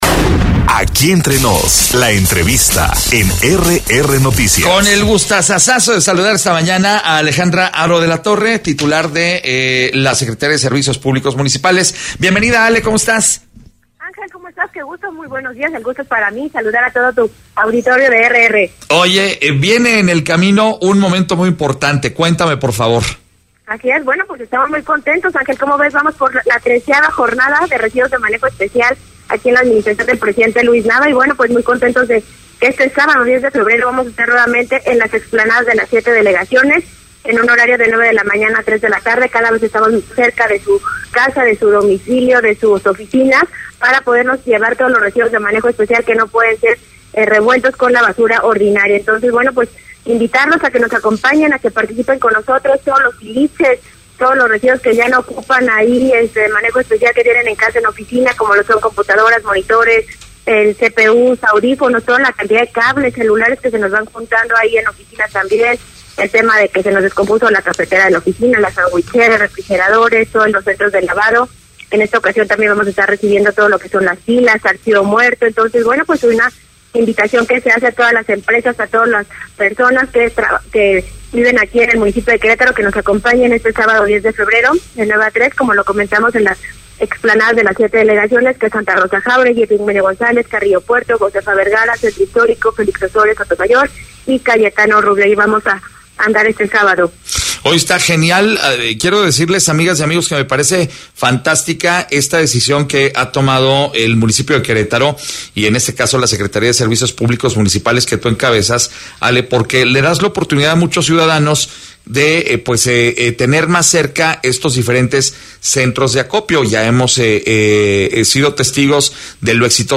EntrevistasOpinión